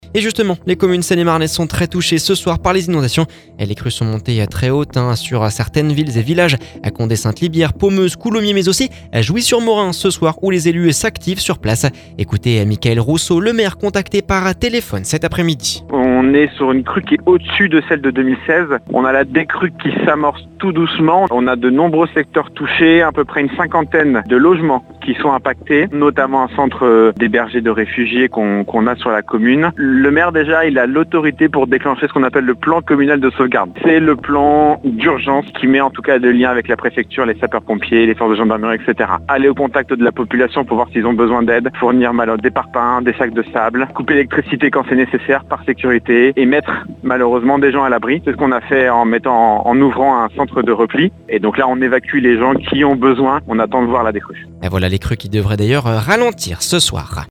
contacté par téléphone cet après-midi…